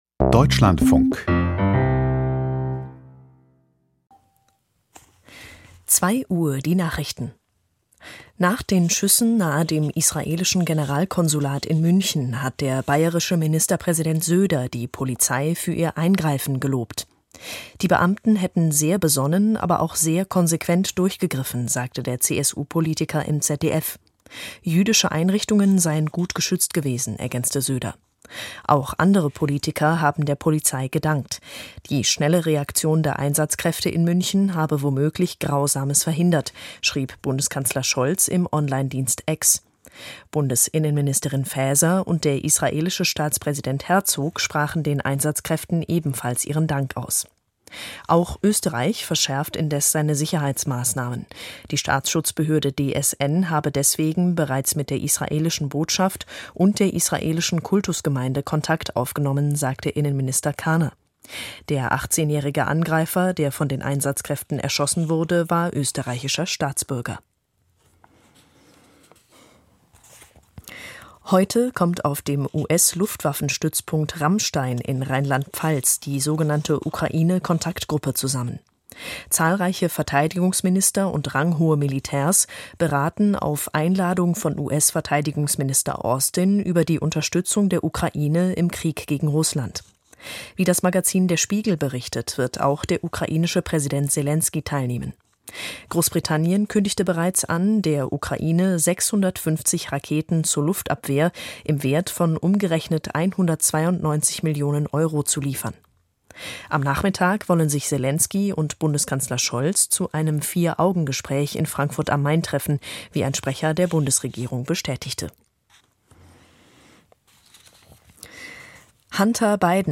Die Deutschlandfunk-Nachrichten vom 06.09.2024, 01:59 Uhr